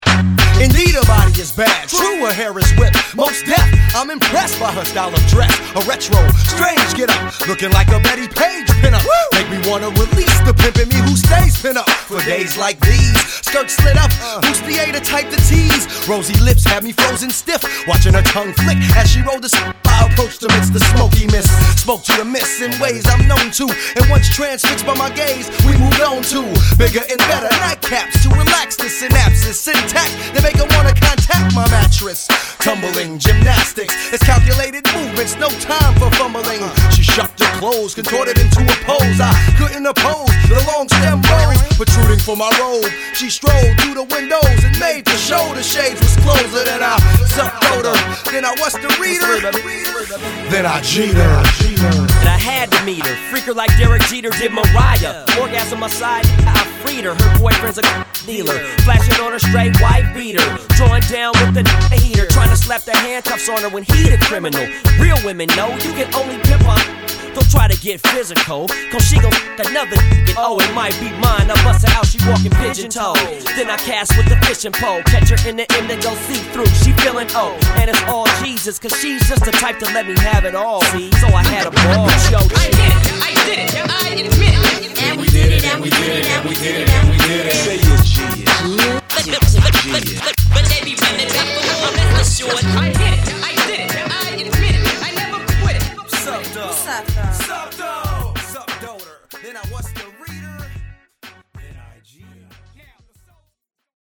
lends scratching talents throughout the record